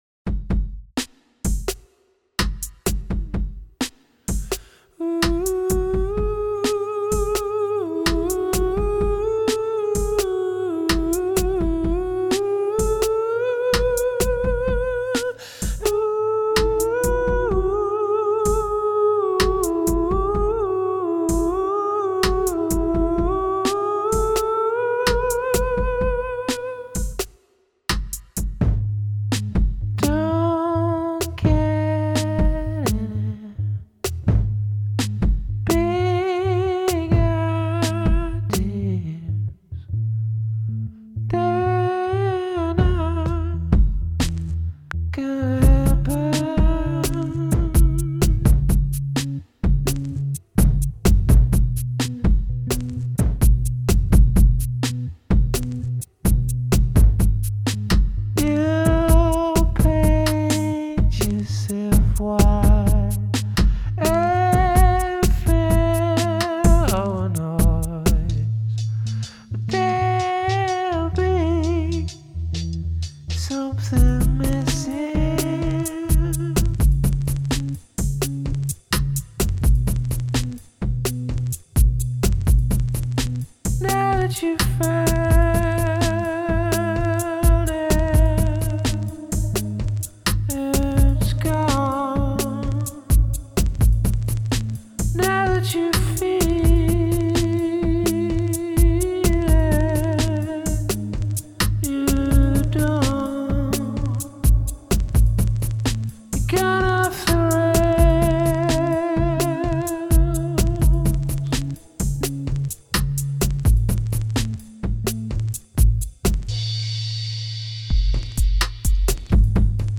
Genre: Electronic
Recorded at my home studio in Brooklyn, April 2008.